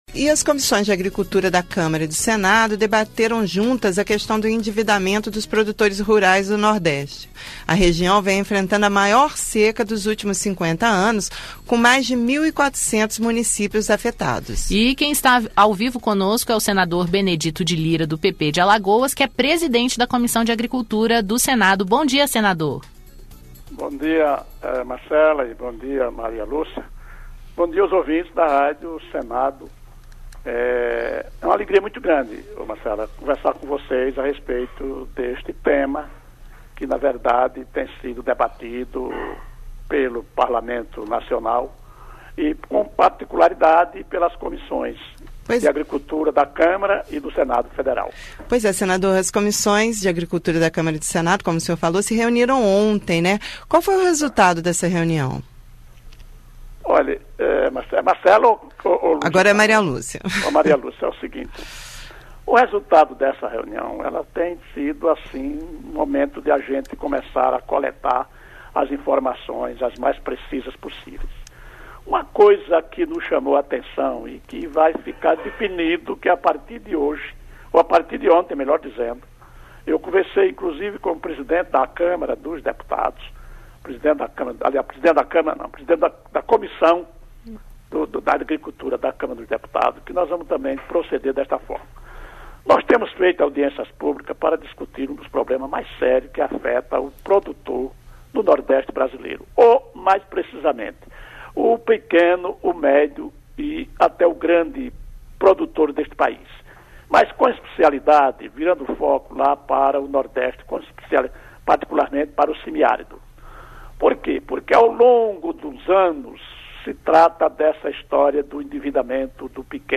Entrevista: Endividamento dos produtores rurais do Nordeste Entrevista com o presidente da Comissão de Agricultura, senador Benedito de Lira.